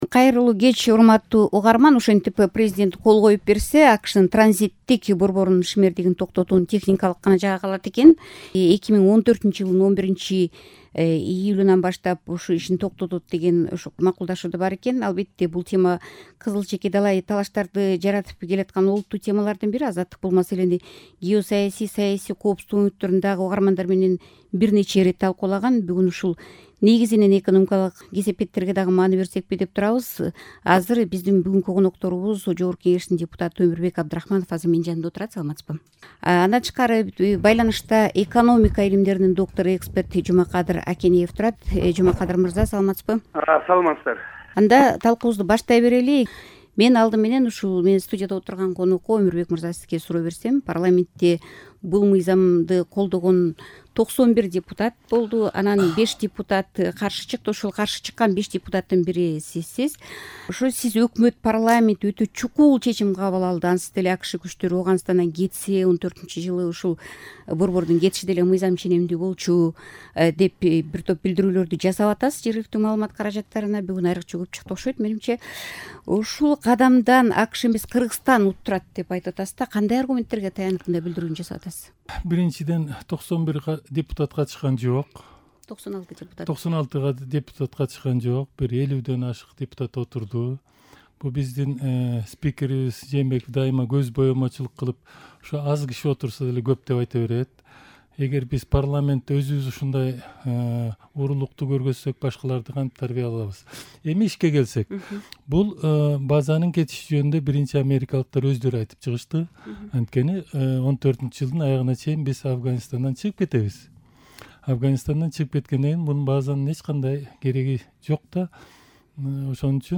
База тууралуу талкуу